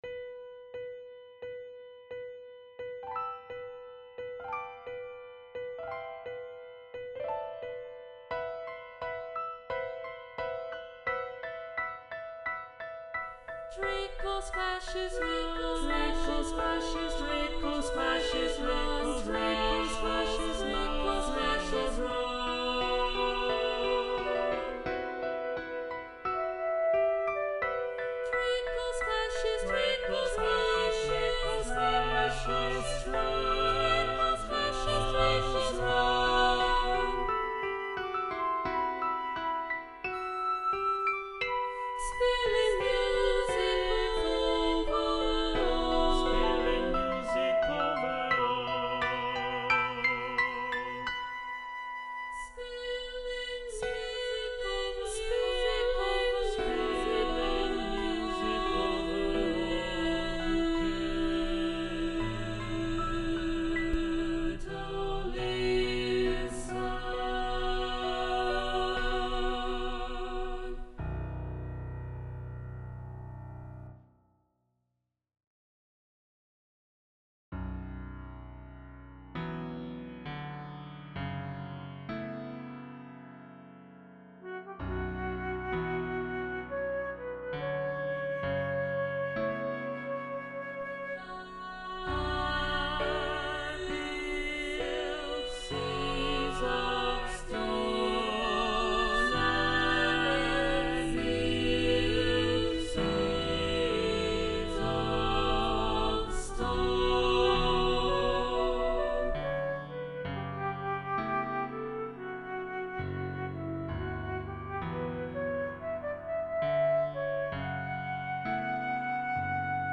calma ; reflexivo
SSATB (5 voces Coro mixto )
flauta y piano
Tonalidad : modal